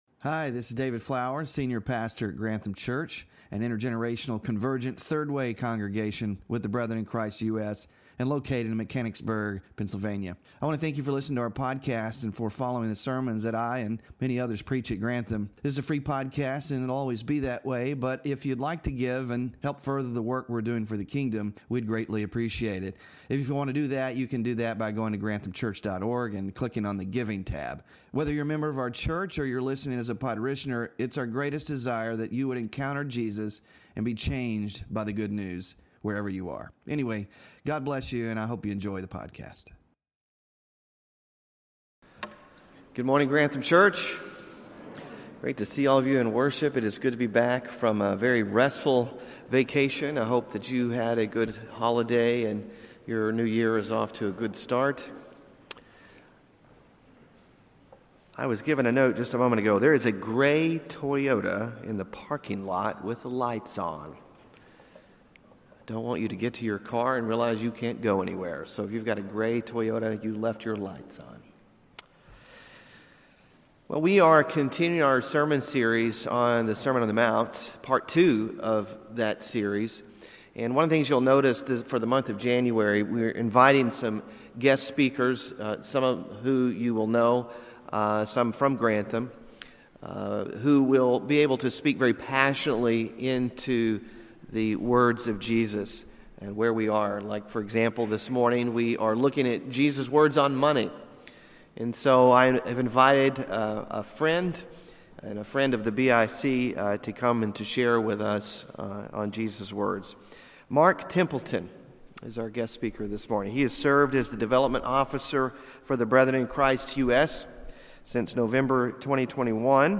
Sermon Focus: Seeking God is a primary goal in our lives.